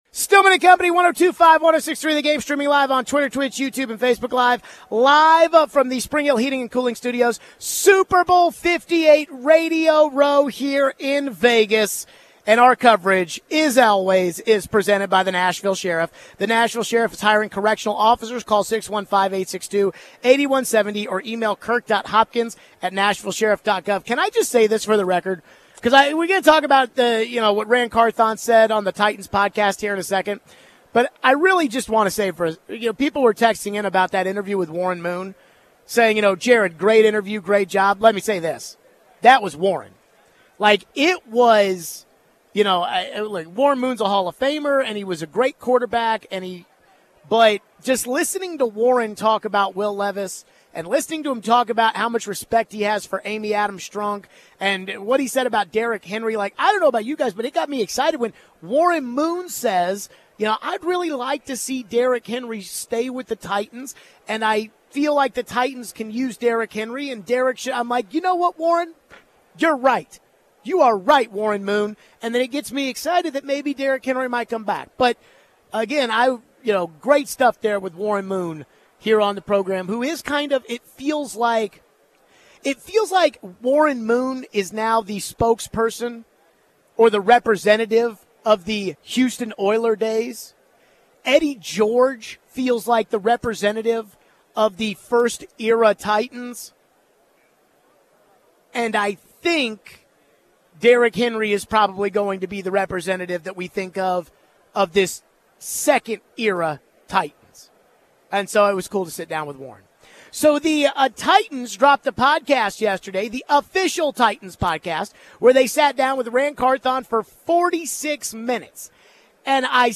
We take your phones.